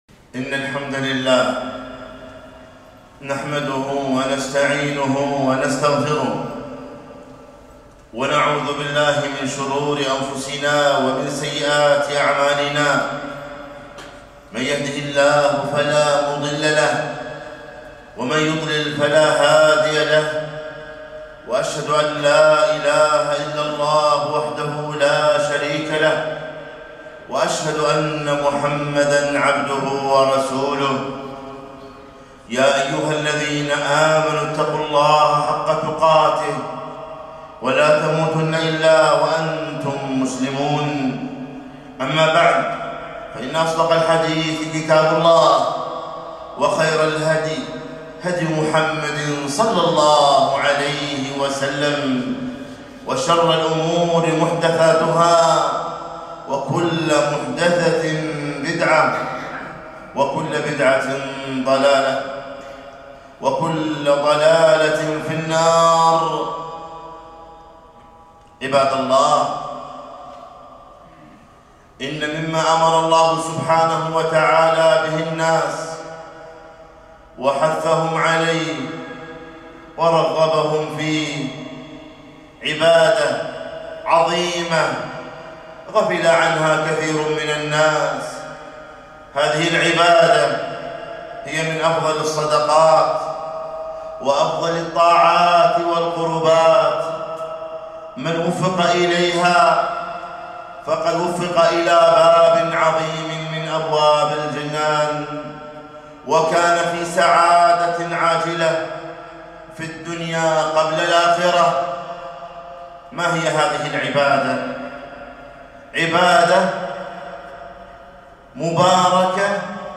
خطبة - إصلاح ذات البين